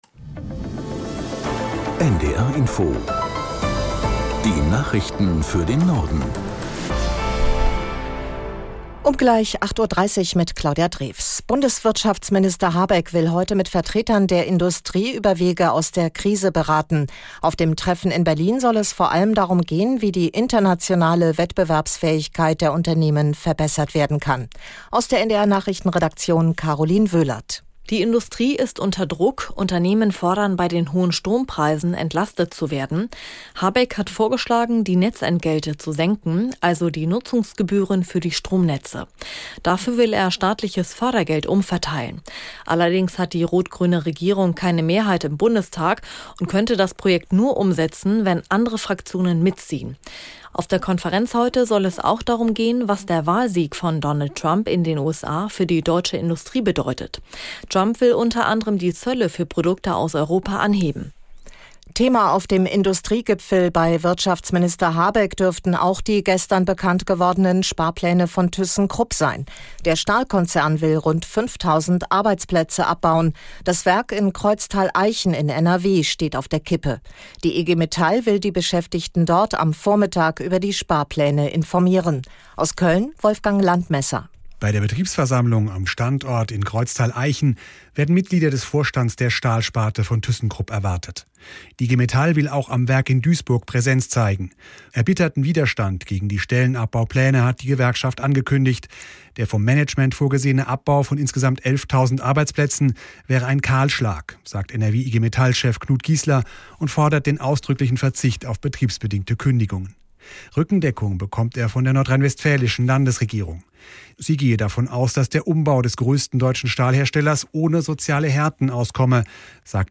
Nachrichten NDR Info Tägliche Nachrichten Die Welt